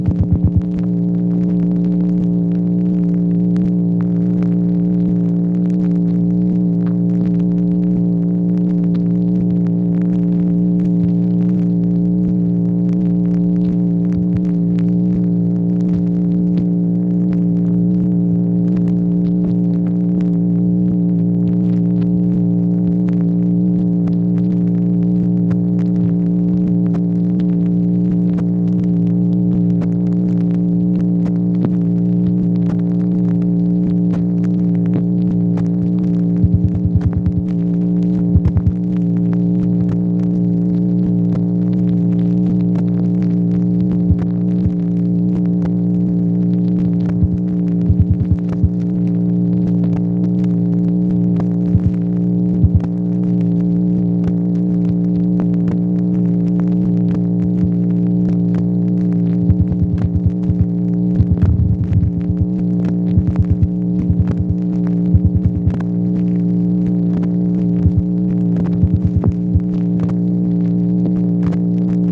MACHINE NOISE, June 26, 1964
Secret White House Tapes | Lyndon B. Johnson Presidency